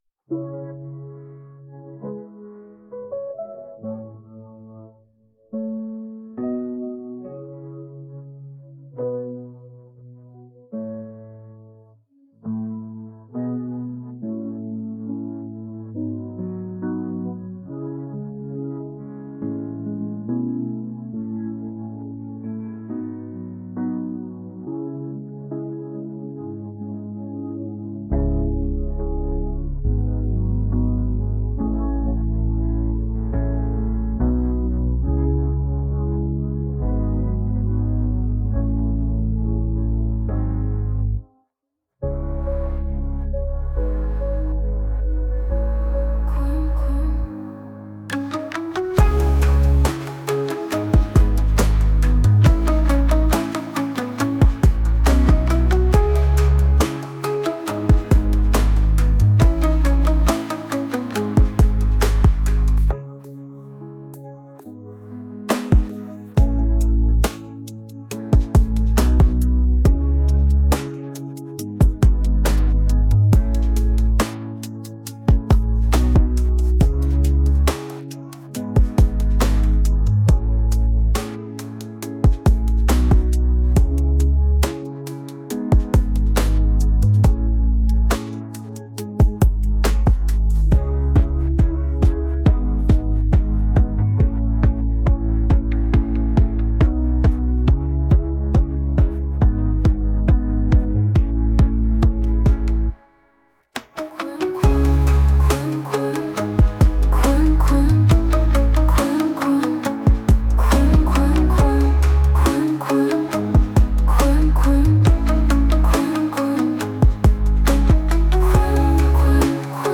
Podkład muzyczny tytuł: Żaba , autor: inteligencja Sztuczna Odsłuchań/Pobrań 4